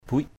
buic.mp3